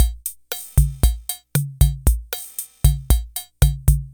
116_TR77AFRO.mp3